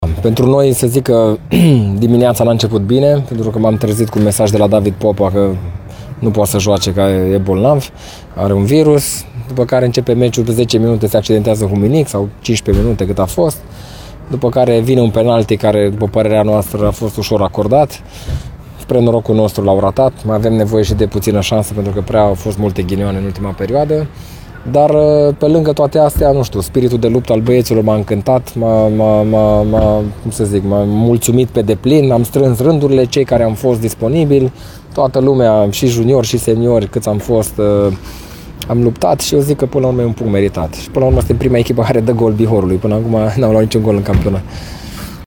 Paul Codrea, antrenorul alb-violeților, despre șirul de ghinioane de dinainte și din timpul partidei, dar și despre atitudinea arătată de elevii săi împotriva liderului, care sunt primii care marchează în poarta bihorenilor, în acest sezon: